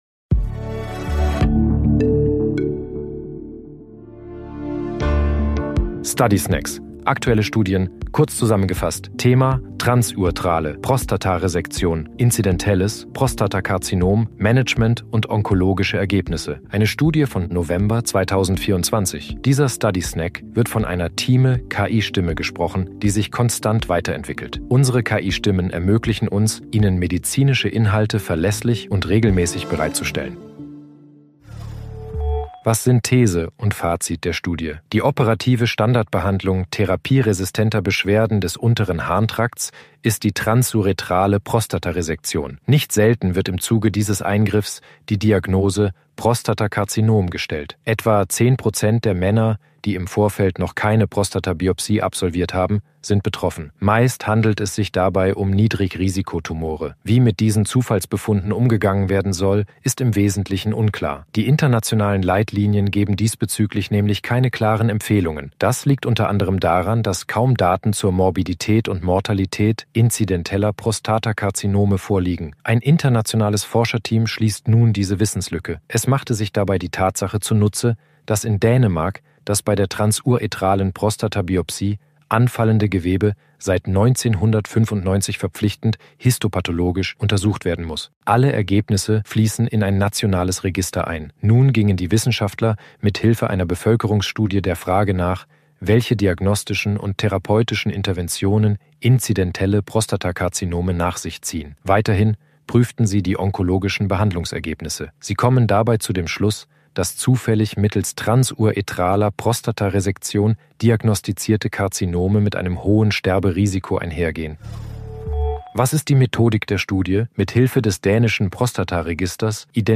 Intelligenz (KI) oder maschineller Übersetzungstechnologie